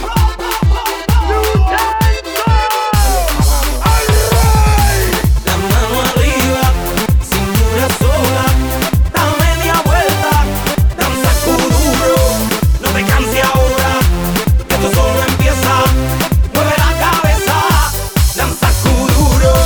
ЗАДОРНЕНЬКО...